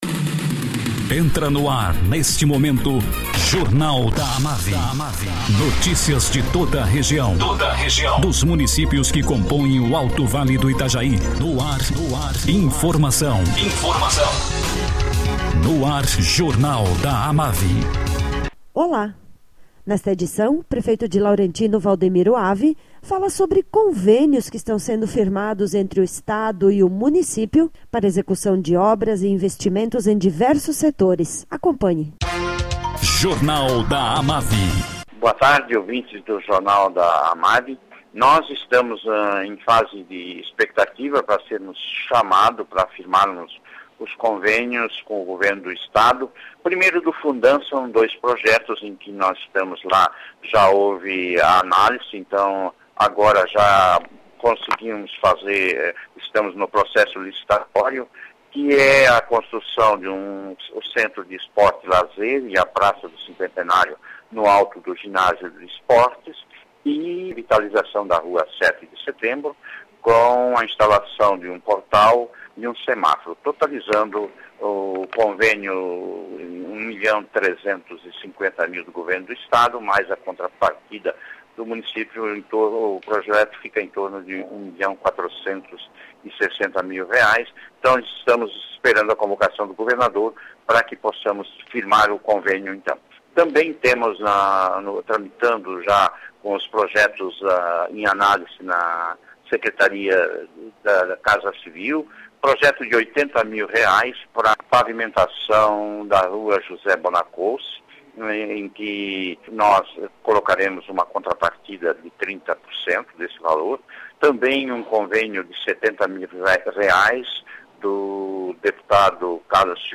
Prefeito de Laurentino, Valdemiro Avi, fala sobre convênios que estão sendo firmados com o Governo do Estado para execução de obras no município.